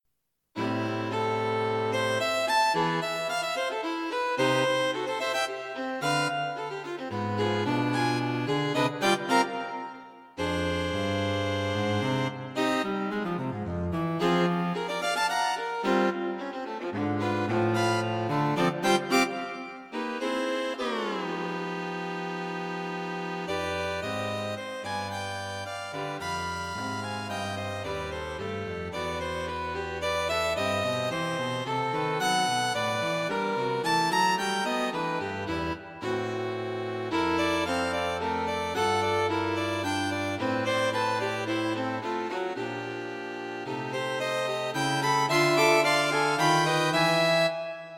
String Quartet for Concert performance